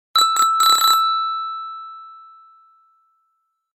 Велосипедный гудок